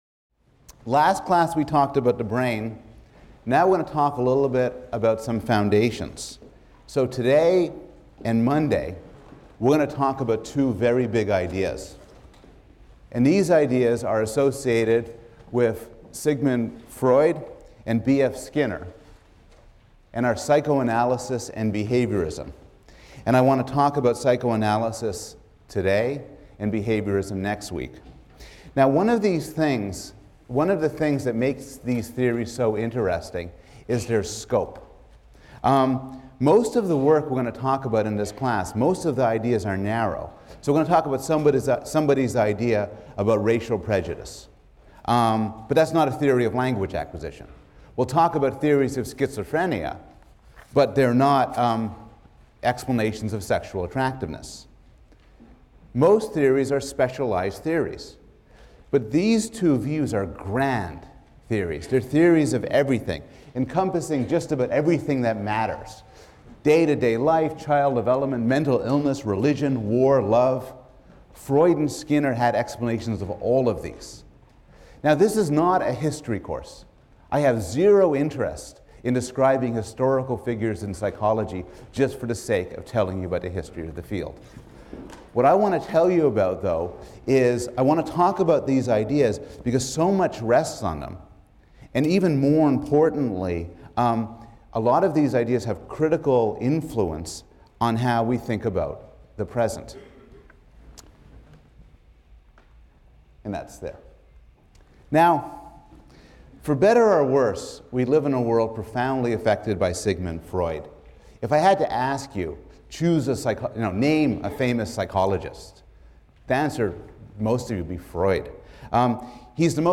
PSYC 110 - Lecture 3 - Foundations: Freud | Open Yale Courses